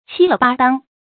七了八当 qī le bā dāng 成语解释 形容十分妥帖。